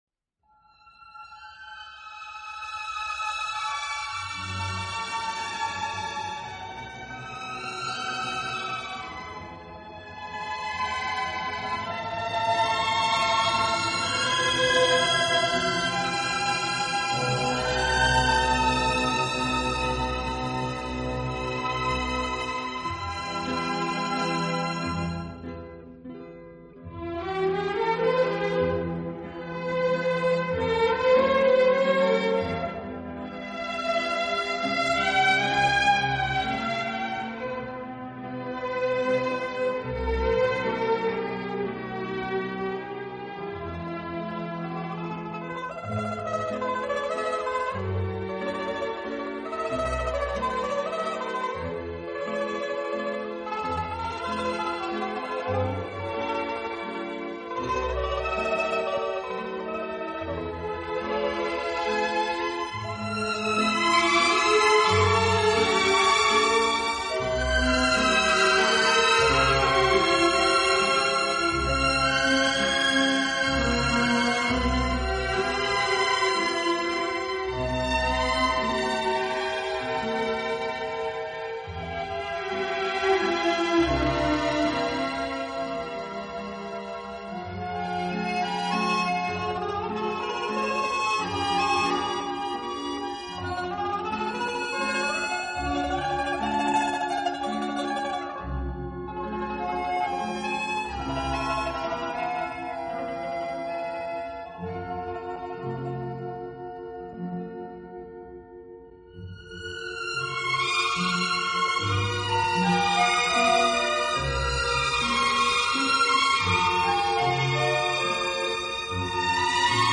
音乐类型：Instrumental
舒展，旋律优美、动听，音响华丽丰满。